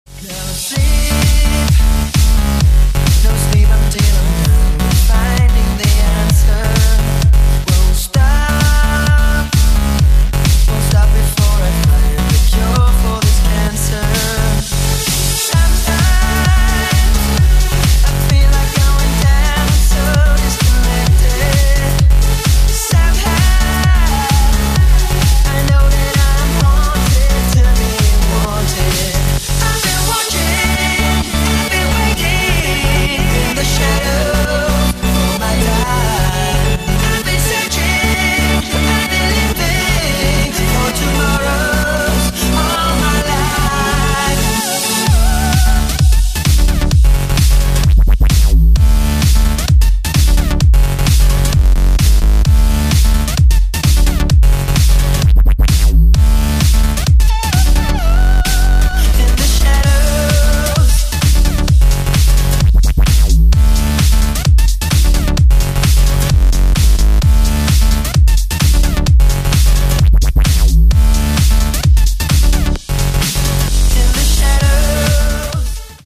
• Качество: 128, Stereo
ритмичные
мужской вокал
громкие
dance
Electronic
электронная музыка
electro house